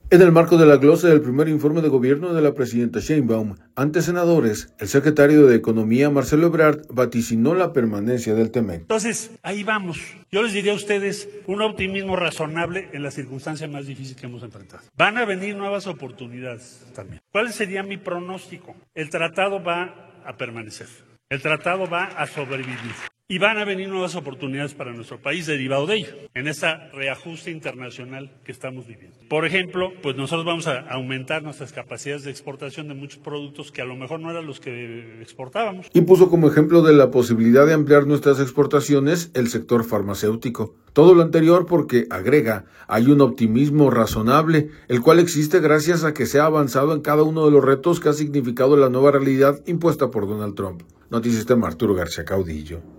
En el marco de la Glosa del primer informe de gobierno de la presidenta Sheinbaum, ante senadores, el secretario de Economía, Marcelo Ebrard, vaticinó la permanencia del TMEC.